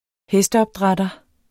Udtale [ ˈhεsdəʌbˌdʁadʌ ]